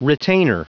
Prononciation du mot retainer en anglais (fichier audio)
Prononciation du mot : retainer